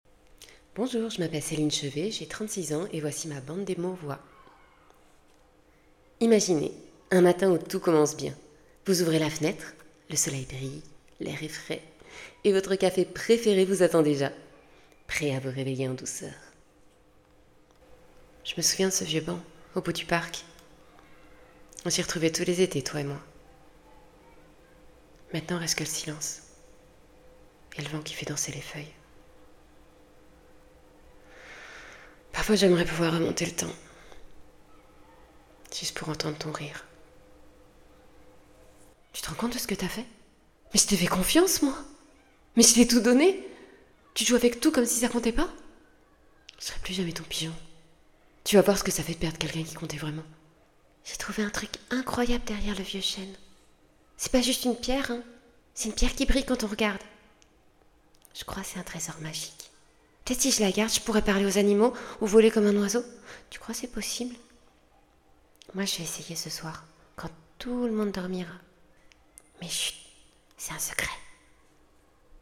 Bande Démo voix 2025
Voix off